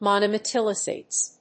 発音記号
• / mὰnoʊmétəlìzm(米国英語)
• / m`ɔnəʊmétəlìzm(英国英語)